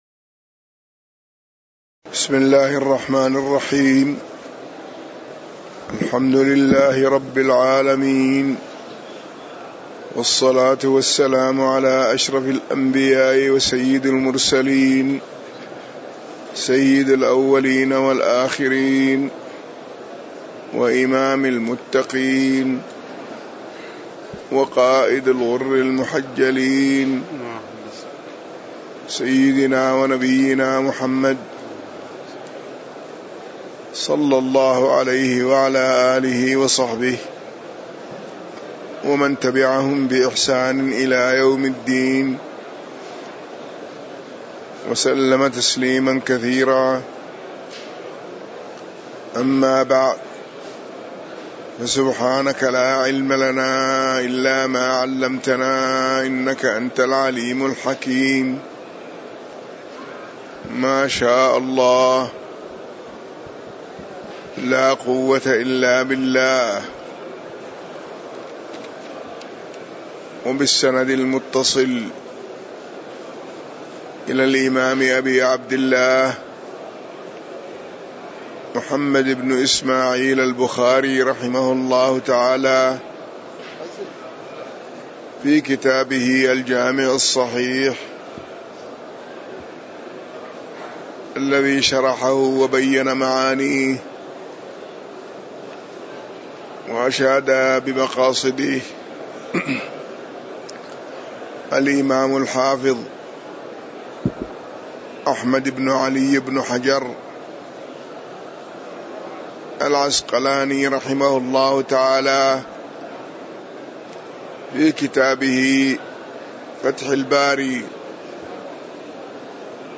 تاريخ النشر ١٤ رمضان ١٤٣٩ هـ المكان: المسجد النبوي الشيخ